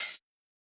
UHH_ElectroHatB_Hit-23.wav